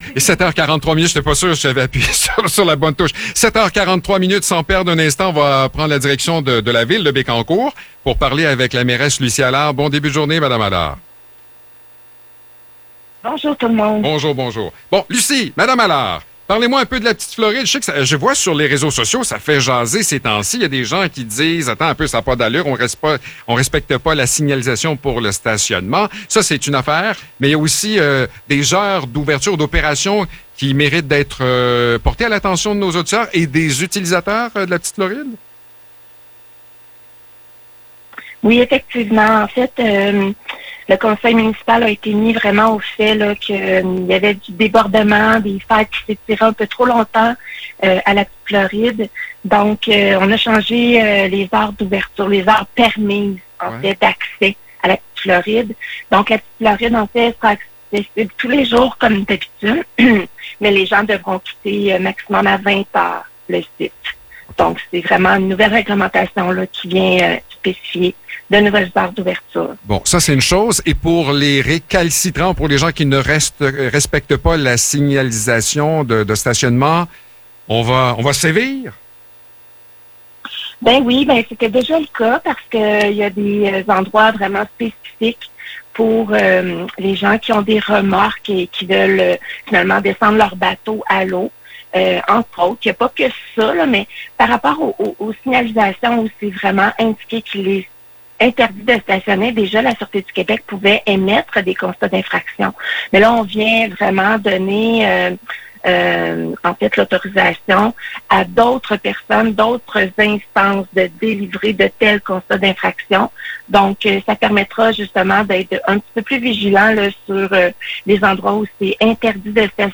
Échange avec la mairesse de Bécancour
Lucie Allard, mairesse de Bécancour, nous parle des dernières concernant Bécancour. Entre autre, l’accès à la Petite Floride et les dégâts d’eau dont plusieurs habitants sont victimes.